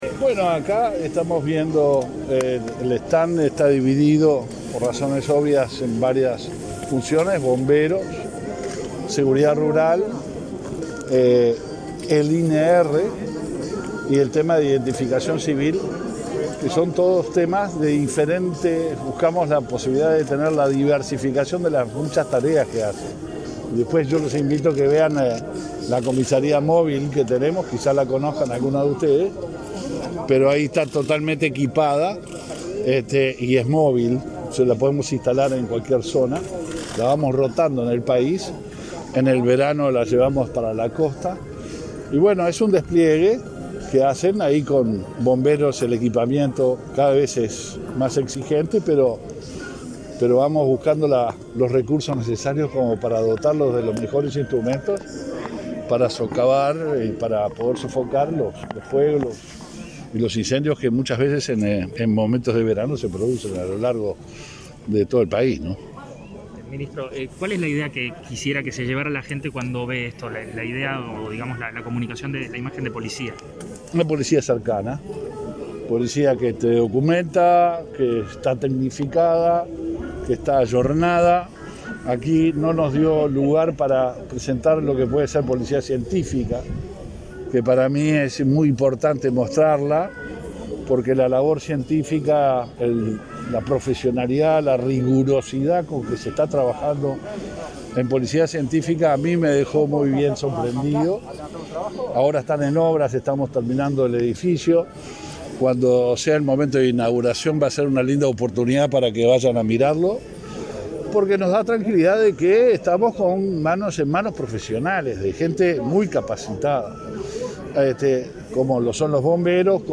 Declaraciones a la prensa del ministro del Interior, Luis Alberto Heber
El Ministerio del Interior inauguró su stand en predio de la 116.ª edición de la Expo Prado.
Tras el evento, el ministro efectuó declaraciones a la prensa.